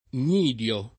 vai all'elenco alfabetico delle voci ingrandisci il carattere 100% rimpicciolisci il carattere stampa invia tramite posta elettronica codividi su Facebook gnidio [ + n’ & d L o ] s. m. (bot.); pl. -di (raro, alla lat., -dii )